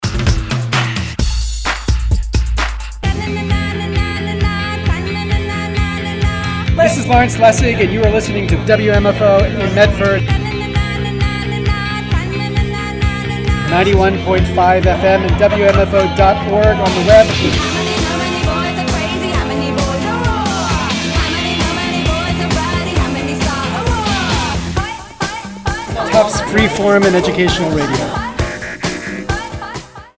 LawrenceLessig_WmfoStationID_GirlTalk_LetMeSeeYou_15s.mp3